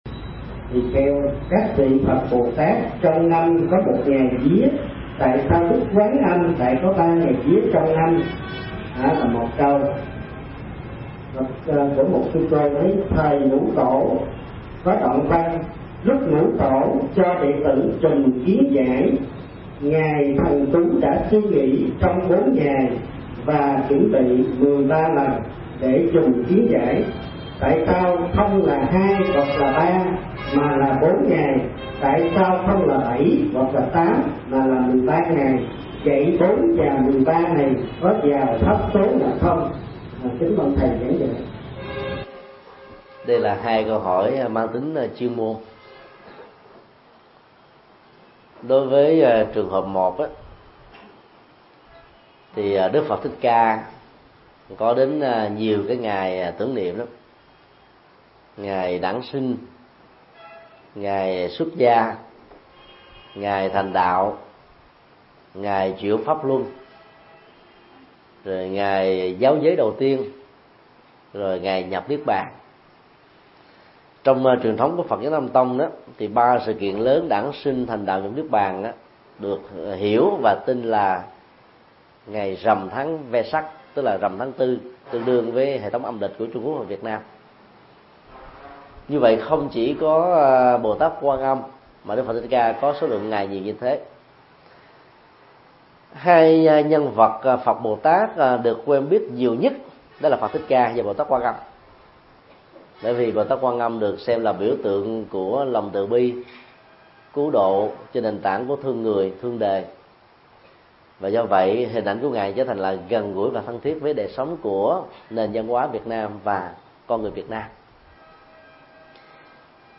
Vấn đáp: Quan Thế Âm Bồ Tát